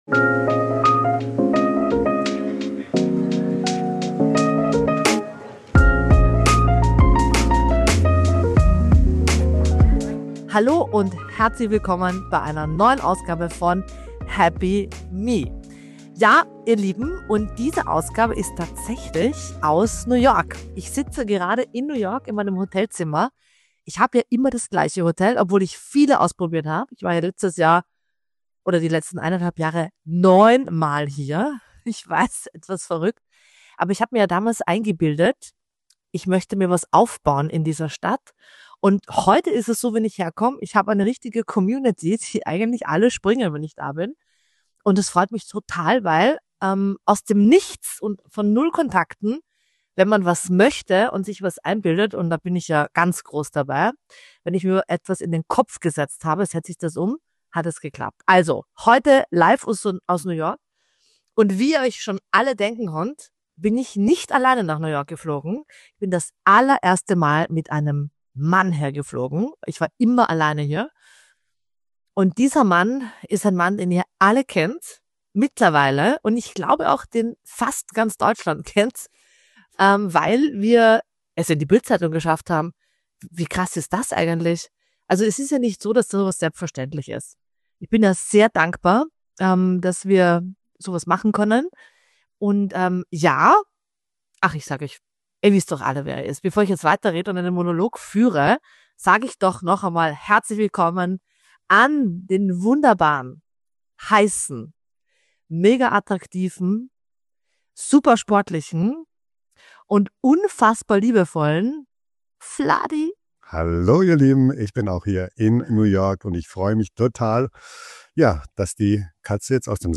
Neue Happy Me Podcast-Folge – live aus New York!